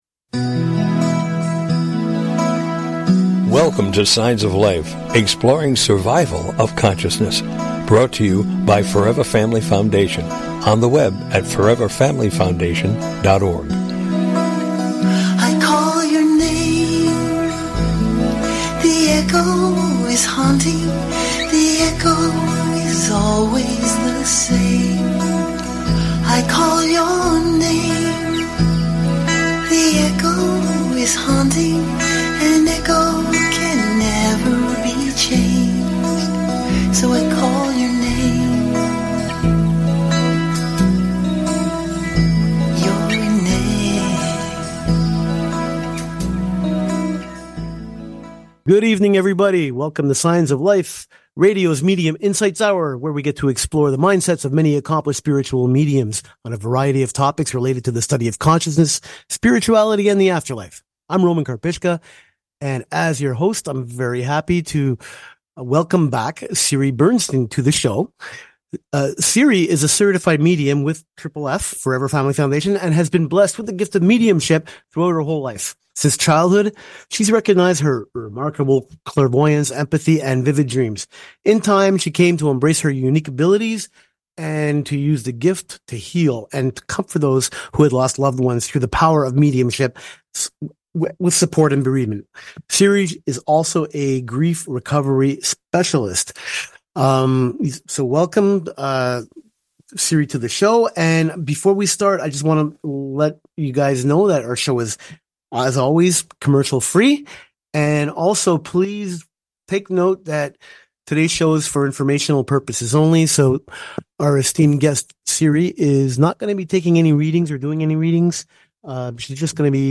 Talk Show Episode
Call In or just listen to top Scientists, Mediums, and Researchers discuss their personal work in the field and answer your most perplexing questions. Topics will include: Mediumship, Near Death Experiences, Death Bed Visions, Reincarnation, Apparitions and Poltergeists, After Death Communication, ESP and Telepathy, Survival of Consciousness, and the list is endless!